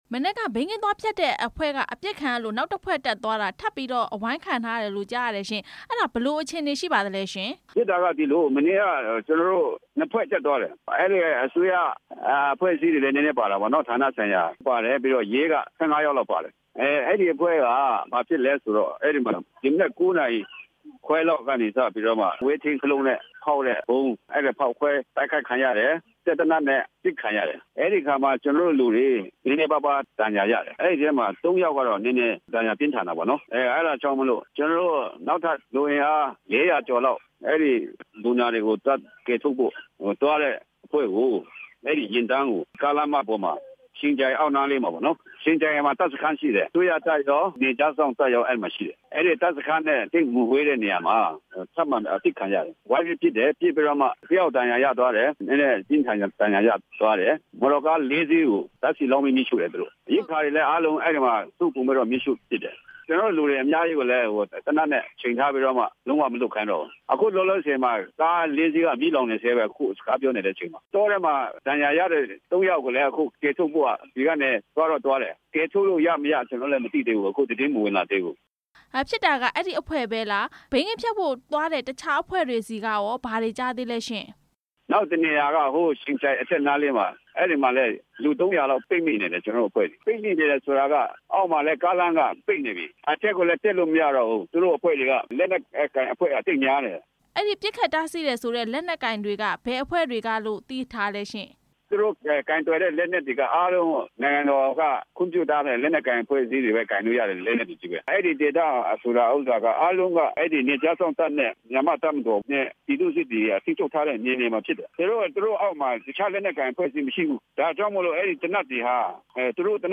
Pat Jasan ဘိန်းဖျက်ဆီးသူတွေ တိုက်ခိုက်ခံရမှု မေးမြန်းချက်